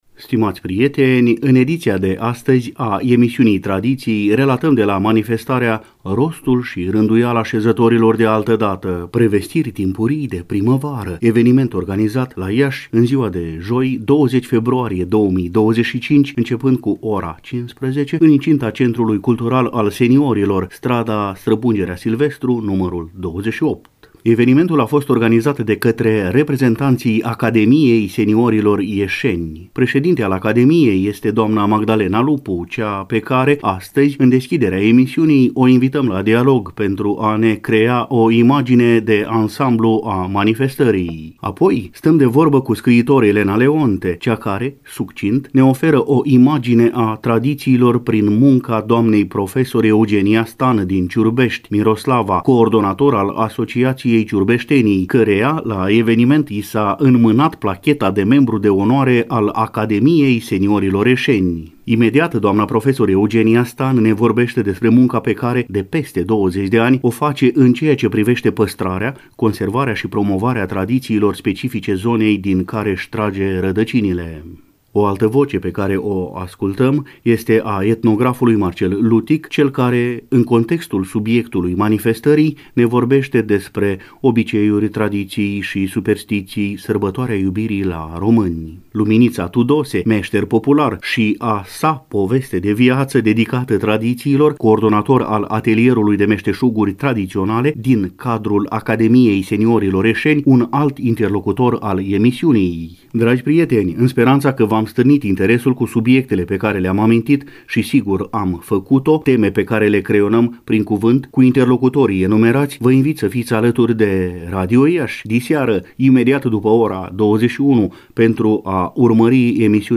Stimați prieteni, în ediția de astăzi a emisiunii Tradiții, relatăm de la manifestarea Rostul și rânduiala șezătorilor de altădată.